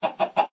sounds / mob / chicken / say3.ogg